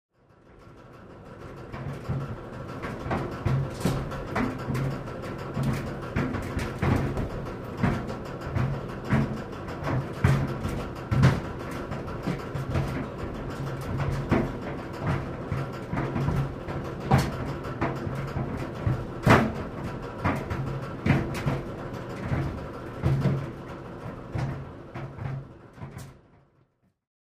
Шум работающей сушилки